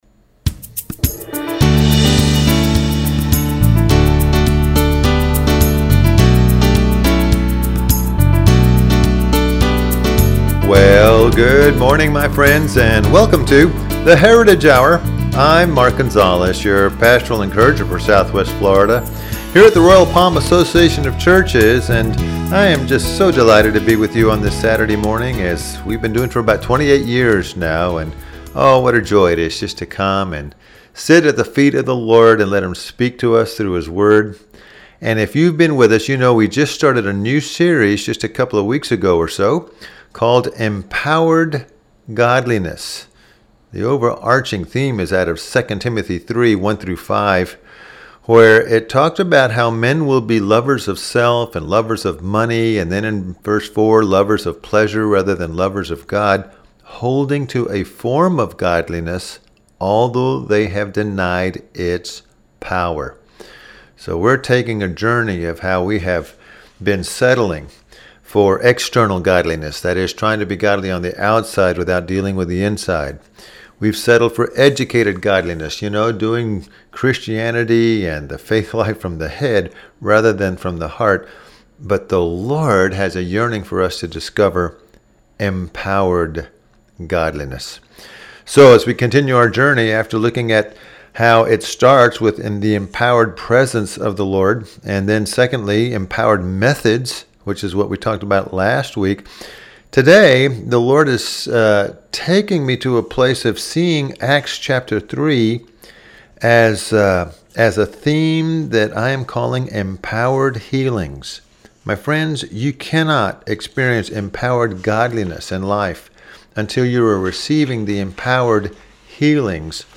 Service Type: Radio Message